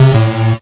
Buzzer2.ogg